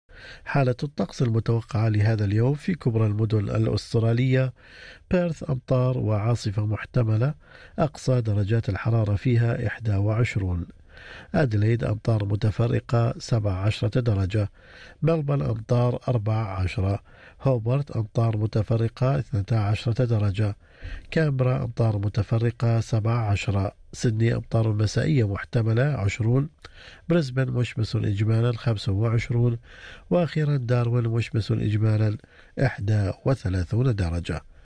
يمكنكم الاستماع الى النشرة الاخبارية كاملة بالضغط على التسجيل الصوتي أعلاه.